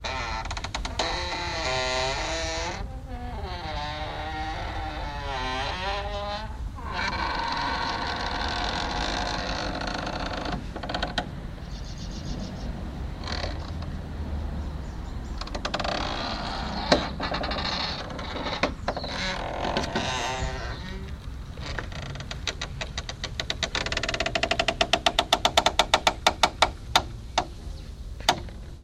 Звуки калитки
Скрип старой деревянной калитки в деревне